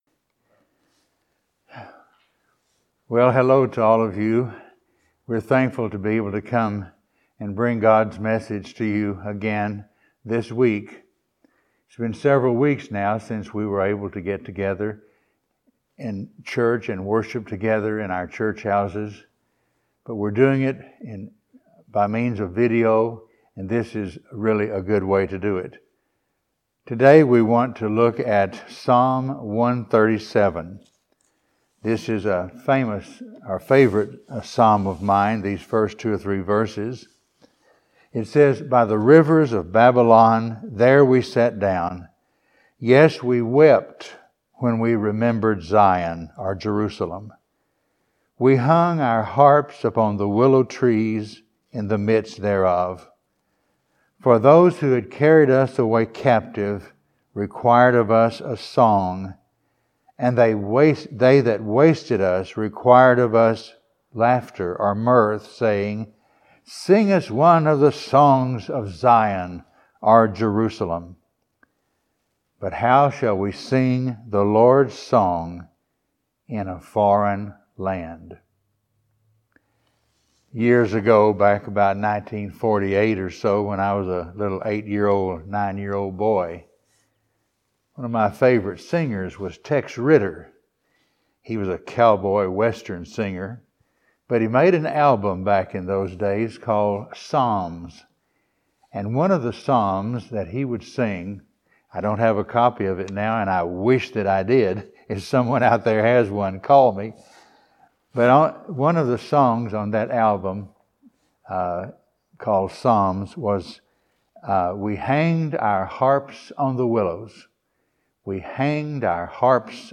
Passage: Psalm 137:1-4 Service Type: Sunday Morning